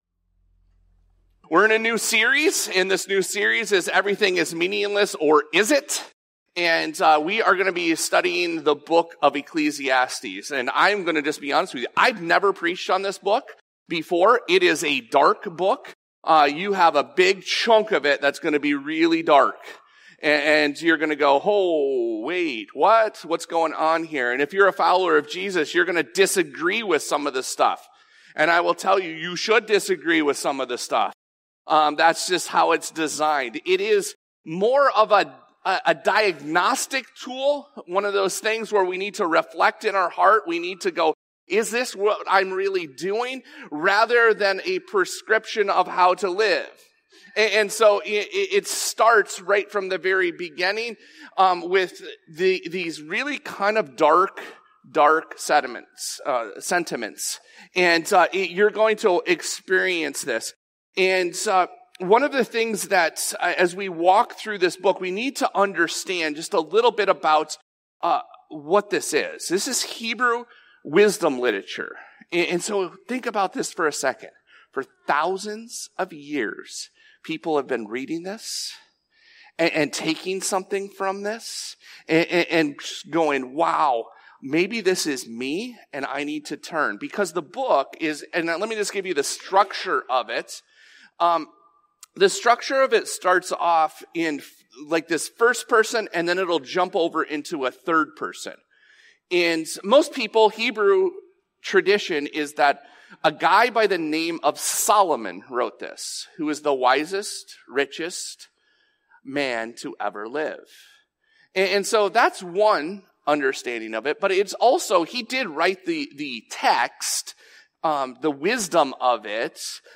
This podcast episode is a Sunday message from Evangel Community Church, Houghton, Michigan, September 7, 2025.